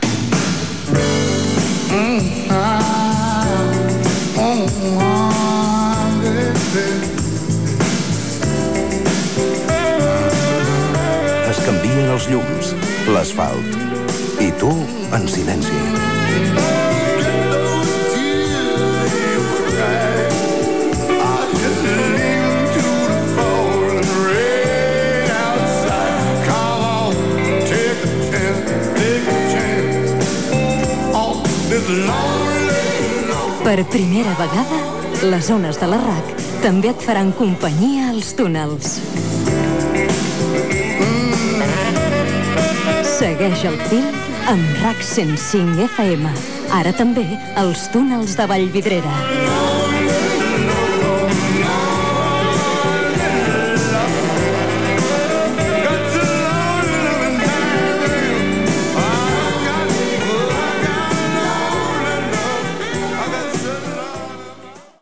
Falca de promoció de RAC 105 amb Túnels de Vallvidrera.